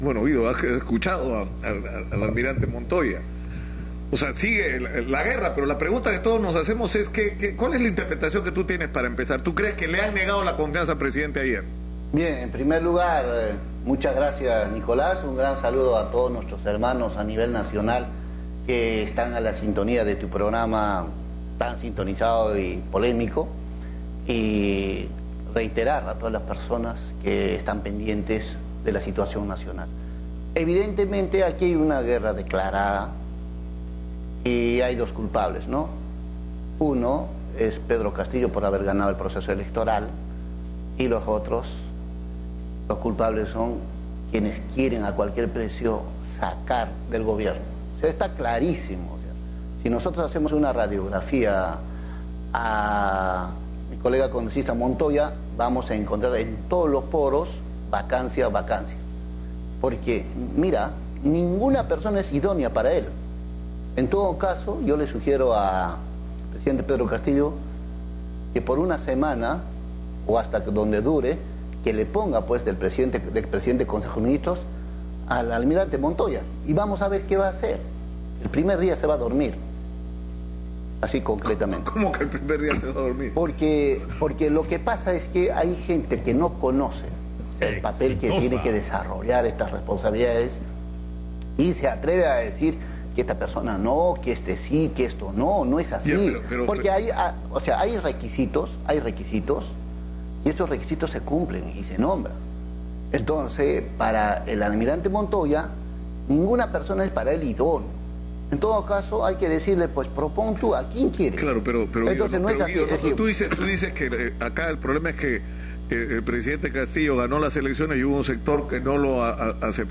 Entrevista a Guido Bellido, congresista (Parte 1)